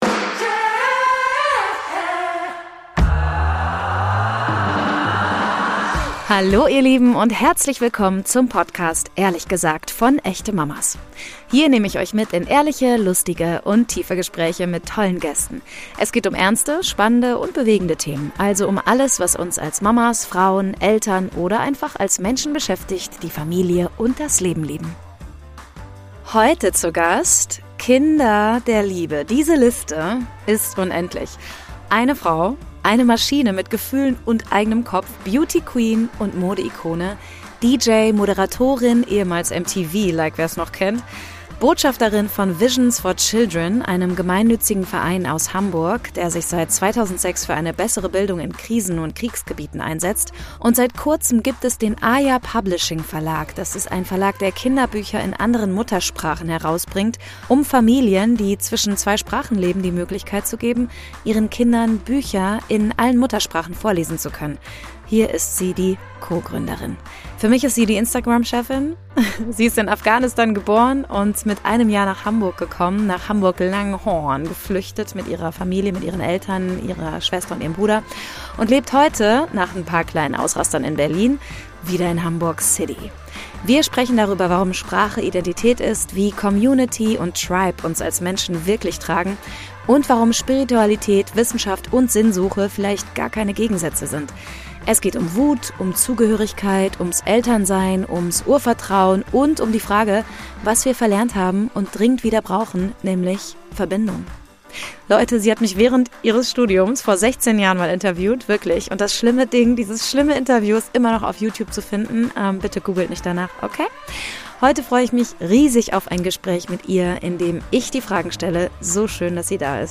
Es geht um Tribe statt Timetable, um Co-Sleeping ohne Scham, um Kinder, Chips und Kontrolle – und um die Sehnsucht nach echter Verbindung. Eine ehrliche, kluge und überraschend persönliche Unterhaltung über Identität, Gemeinschaft und das, was wir vielleicht verlernt haben: Urvertrauen.